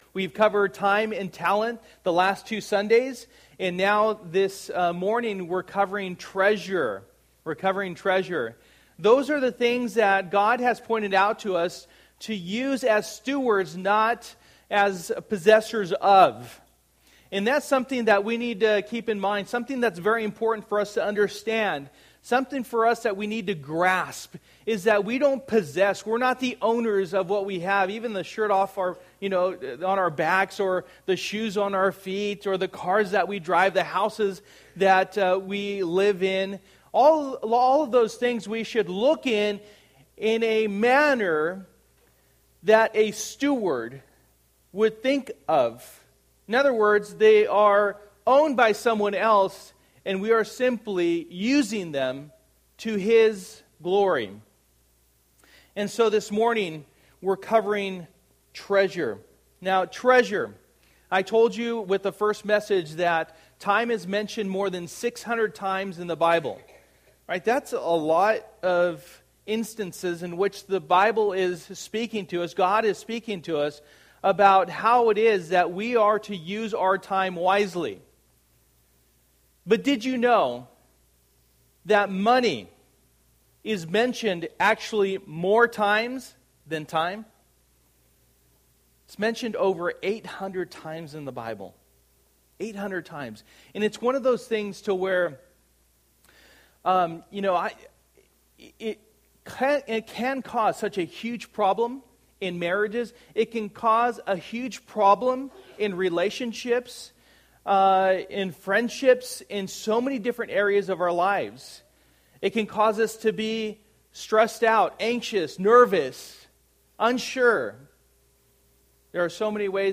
and Treasure Passage: Matthew 6:19-24 Service: Sunday Morning %todo_render% « Making the Best Use of Talents For The Time is Near